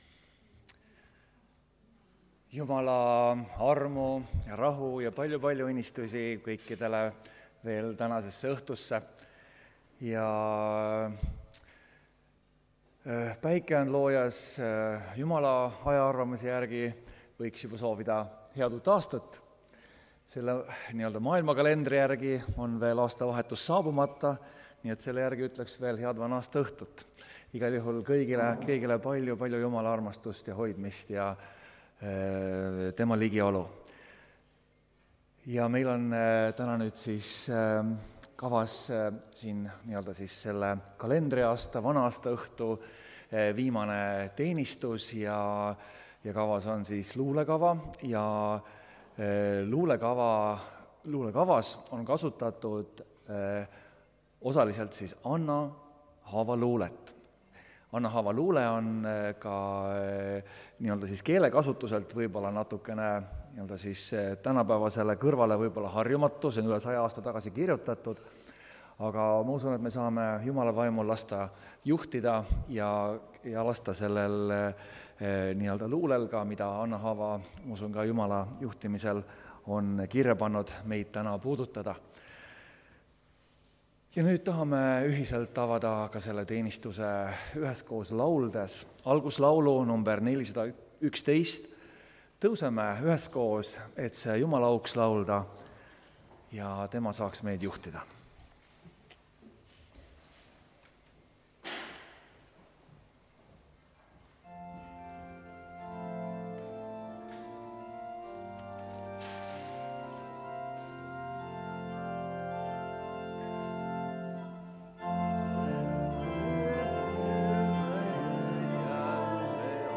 Aastavahetuse luulekava (Tallinnas)
Koosolekute helisalvestused